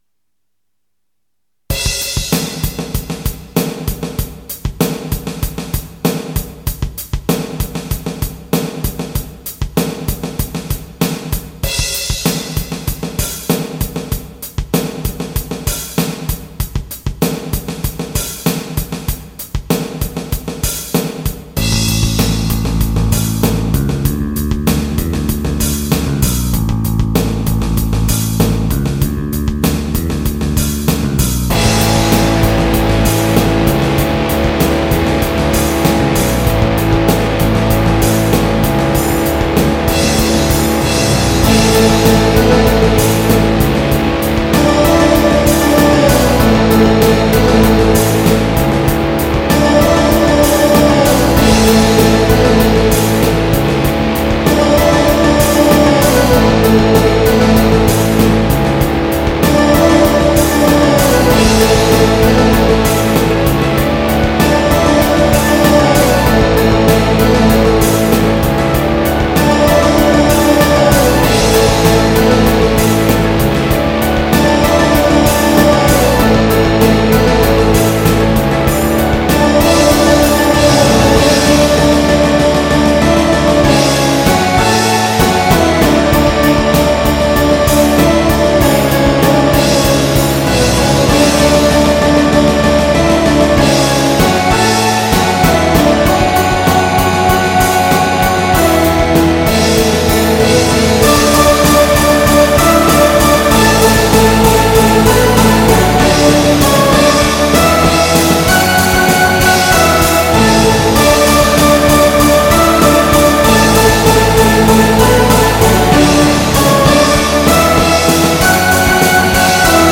Genre：Rock